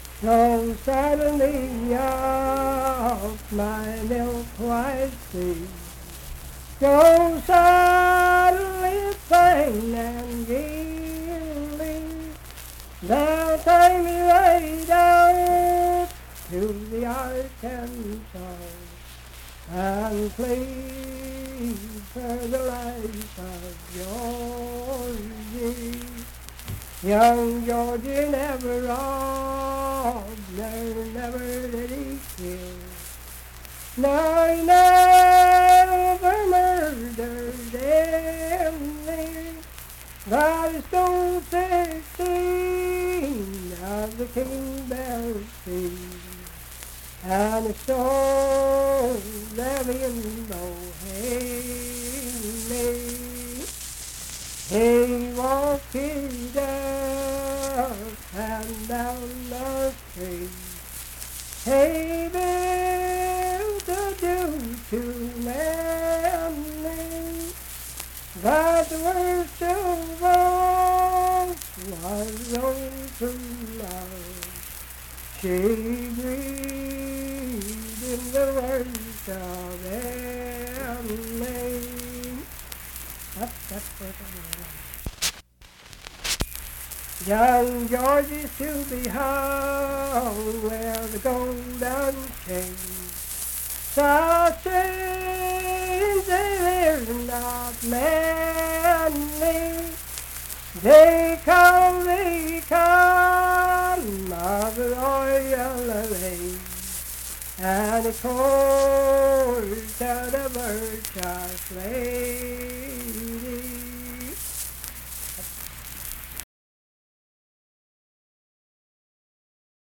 Unaccompanied vocal music
Verse-refrain 4(4).
Performed in Ivydale, Clay County, WV.
Voice (sung)